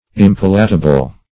Impalatable \Im*pal"a*ta*ble\, a.